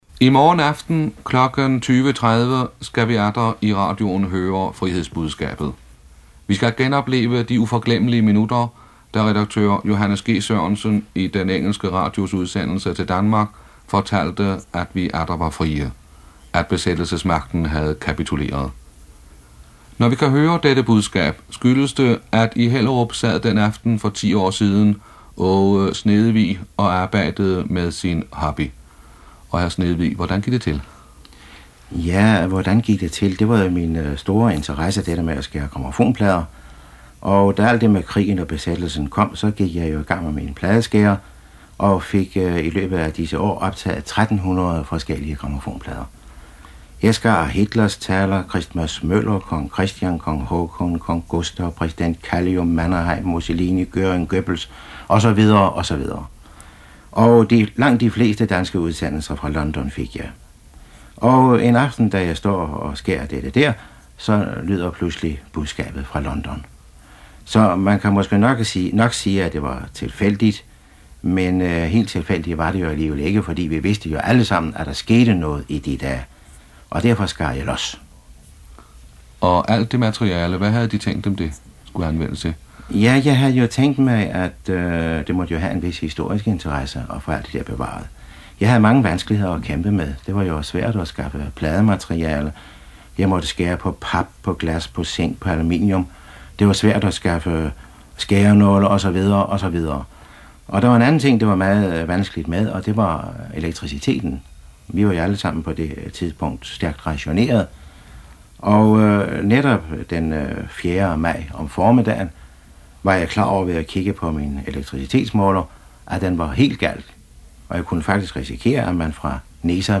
Redaktør Johs. G. Sørensen startede oplæsningen i BBCs danske udsendelse kl. 20.30 efter de kendte indledende signaler med morsetegnede for V "...-" og brudstykke af Prins Jørgens March.
Helt at undgå de tyske støjsendere kunne han ikke, som man også hører på mange af hans optagelser.
Et enkelt sted fornemmer man i optagelsen (overspilning til spolebånd) ) fra 4. maj 1945, at man kan høre skift fra en skive til en anden, hvor synkroniseringen ikke har været 100% under afspilningen.